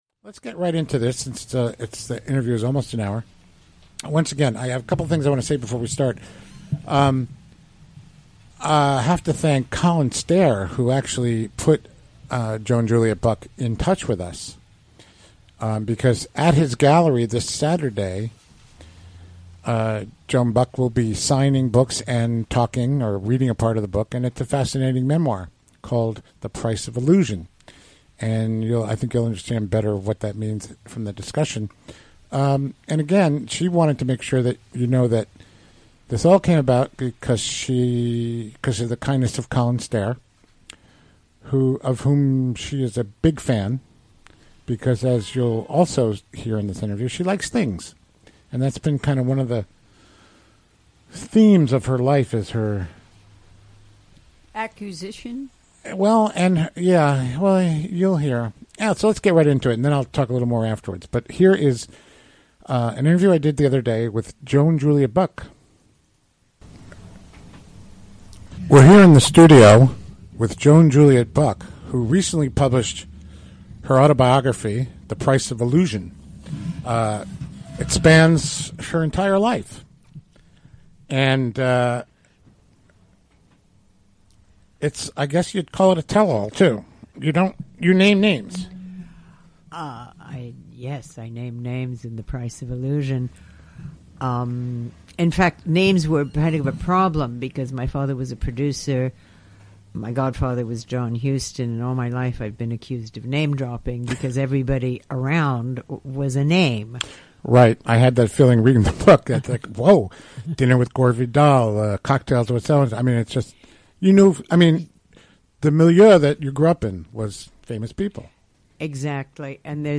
Recorded Tue., May 23, 2017, in the WGXC Hudson Studio.